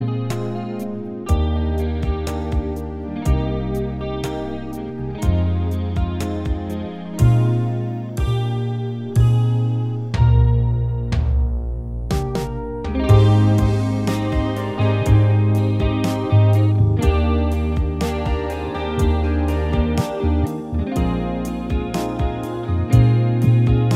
Minus Guitars Pop (1970s) 4:12 Buy £1.50